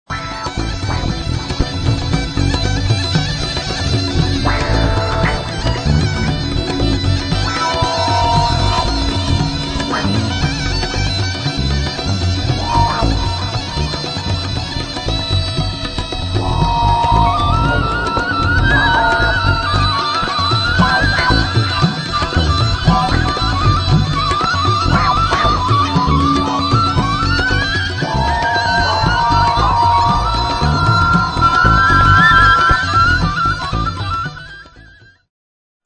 Kaval (Bulgarische Doppelflöte)
Gaida (Bulgarischer Dudelsack)
Tabla & Percussion